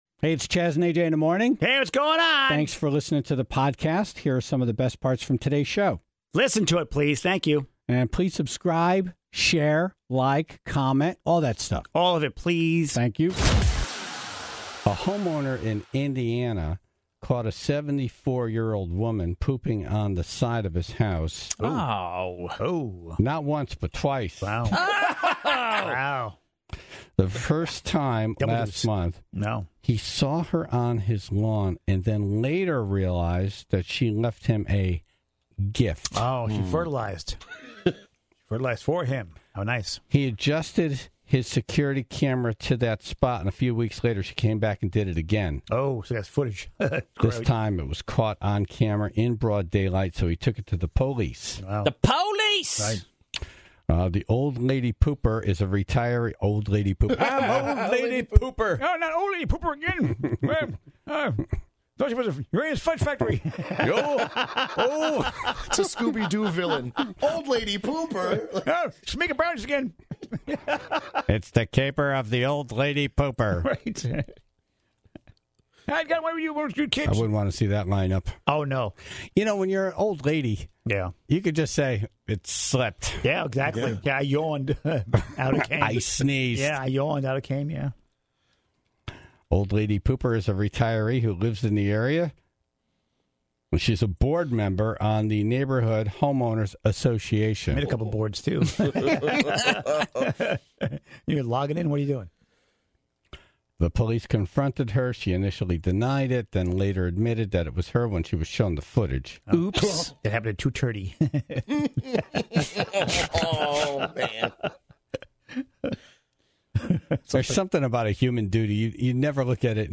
What should "normal" people do when an accident happens in front of them? Tribe members with EMT experience called in to give some advice.